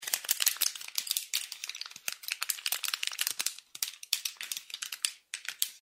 На этой странице собрана коллекция звуков жевания жвачки.
Звук жевания жвачки